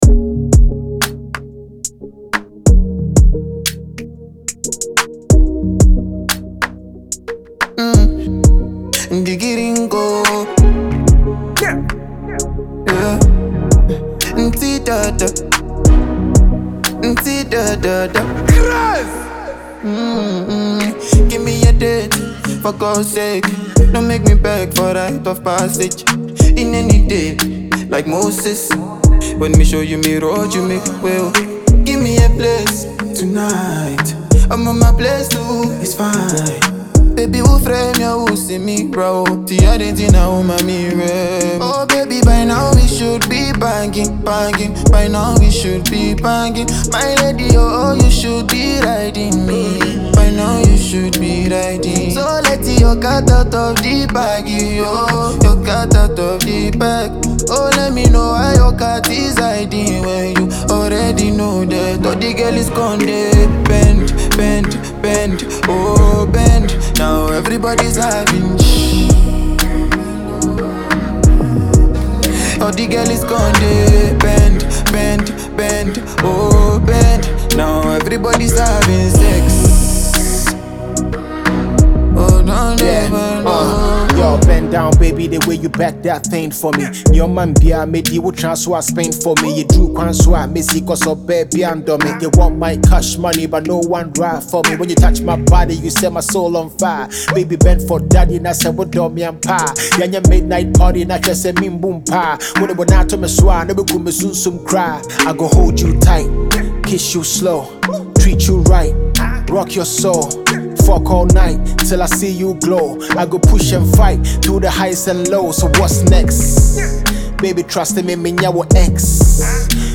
With soulful melodies and clean production
Short, catchy, and packed with emotion